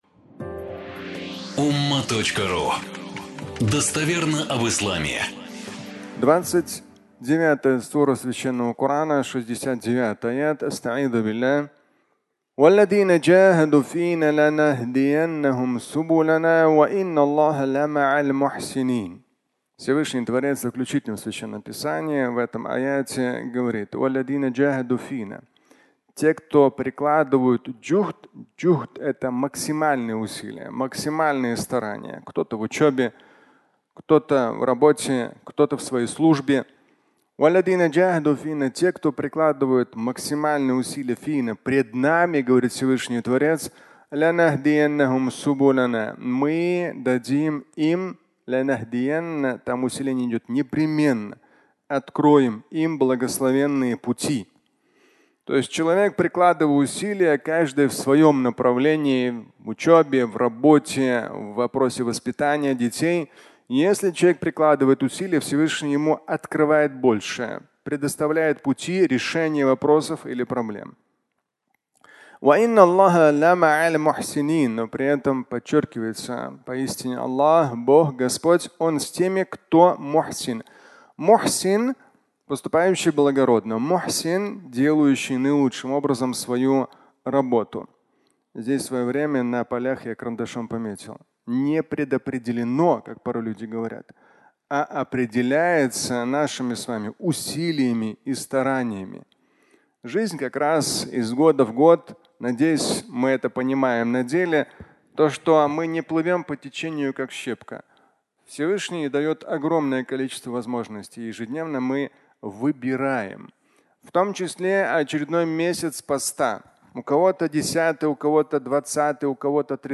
Усилия открывают (аудиолекция)
Фрагмент праздничной проповеди